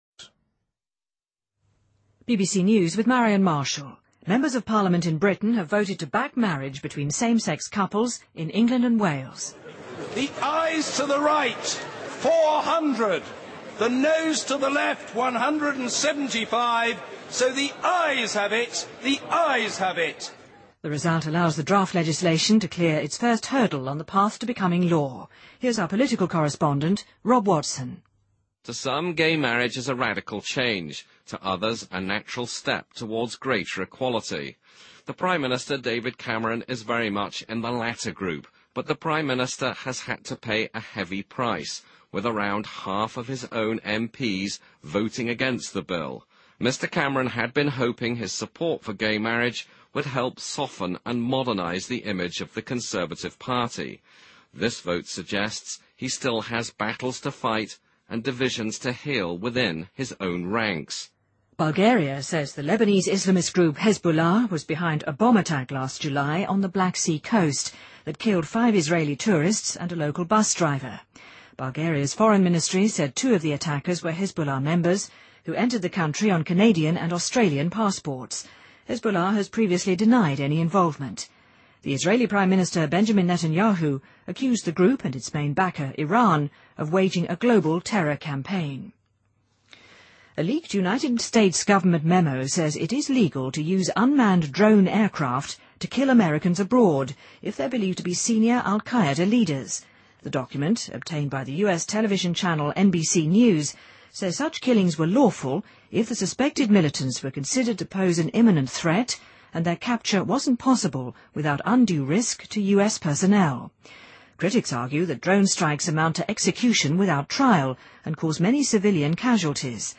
BBC news,2013-02-06